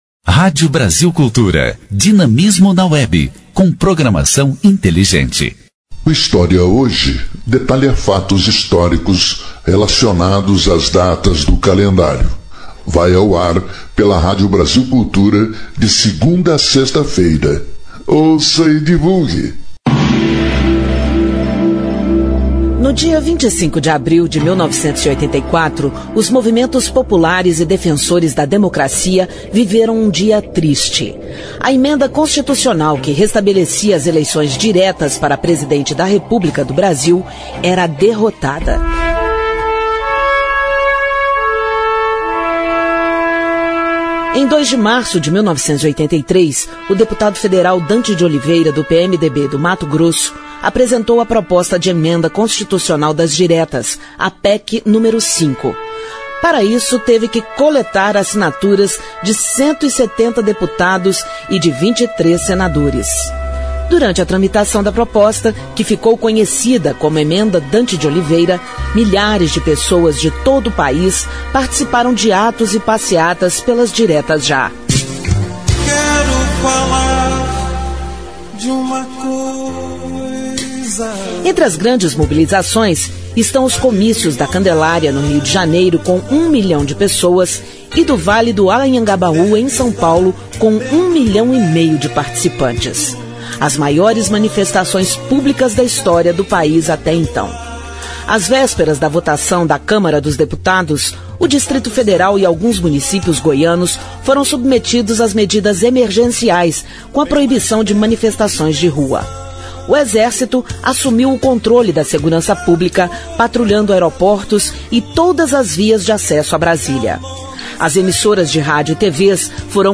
História Hoje : Programete sobre fatos históricos relacionados às datas do calendário. Vai ao ar pela Rádio Brasil Cultu ra de segunda a sexta-feira.